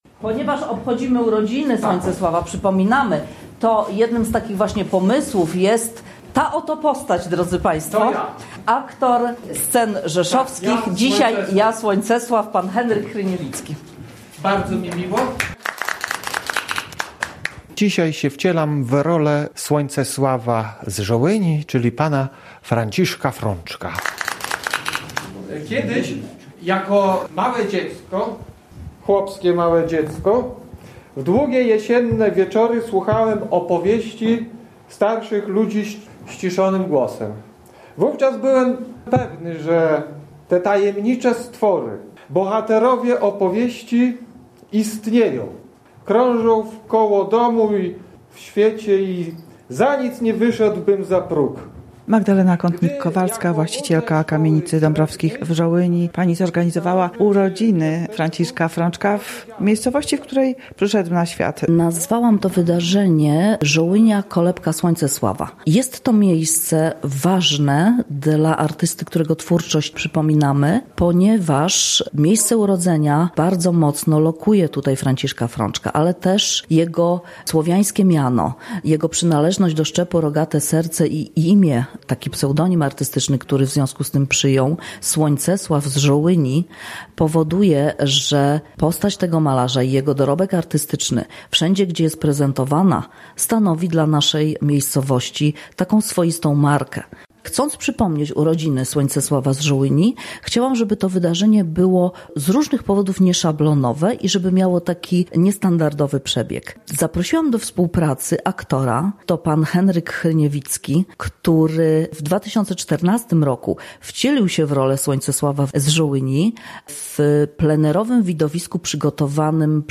Obchody Roku Franciszka Frączka zainaugurowało wydarzenie artystyczne w Kamienicy Dąbrowskich w Żołyni, które odbyło się 25 stycznia, czyli dokładnie w rocznicę jego urodzin w 1908 roku.